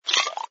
sfx_slurp_bottle06.wav